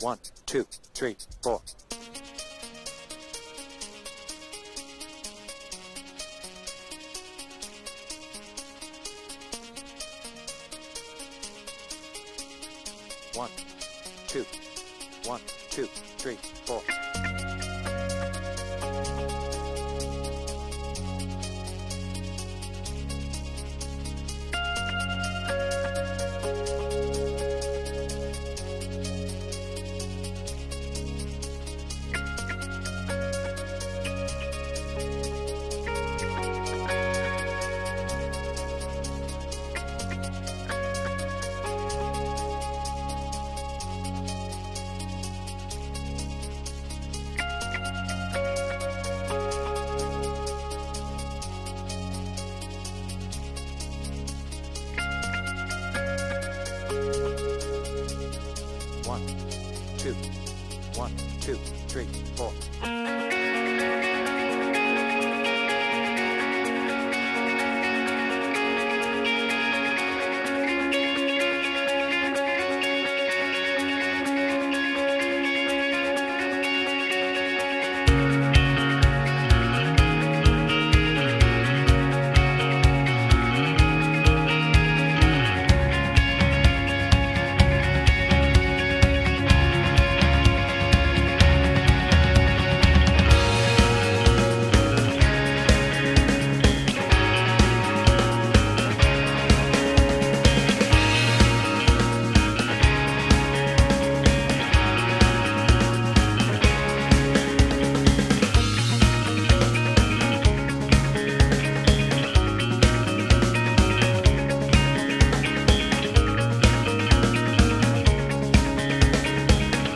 BPM : 126
Without vocals